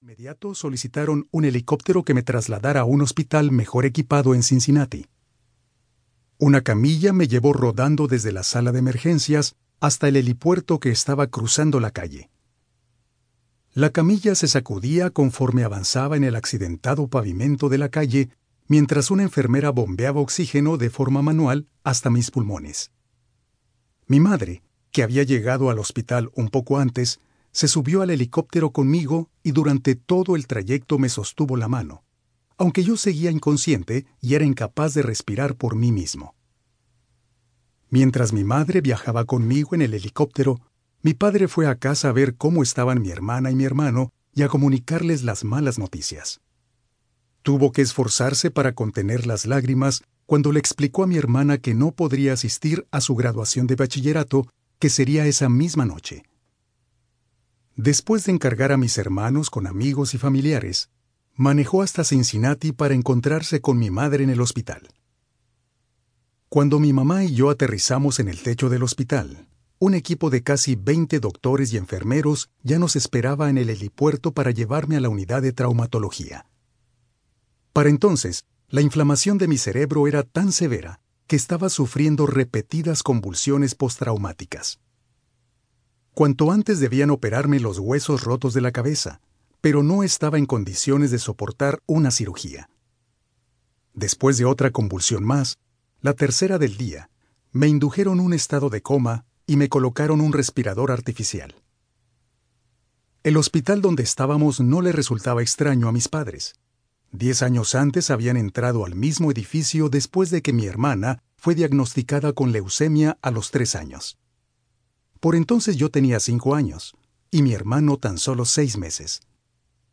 👇 MIRA LA LISTA COMPLETA Y ESCUCHA LA MUESTRA DE CADA AUDIOLiBRO 👇